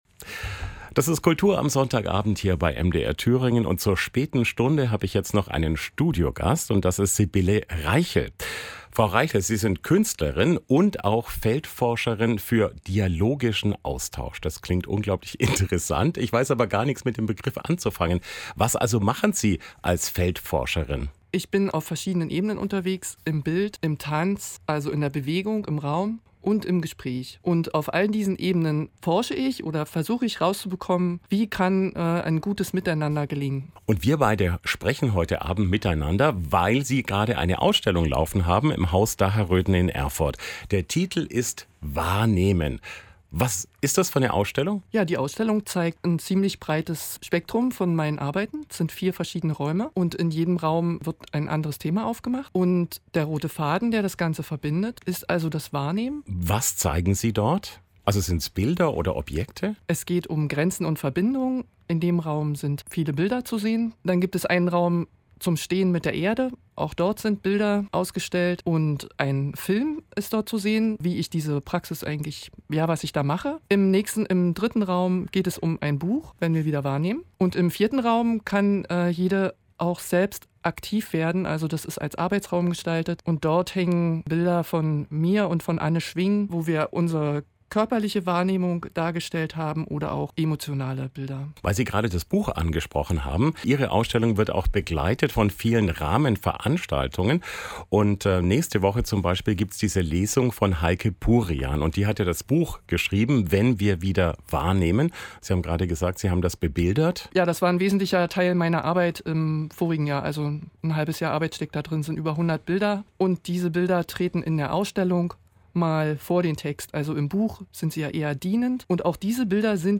Interview für "Kultur am Sonntagabend"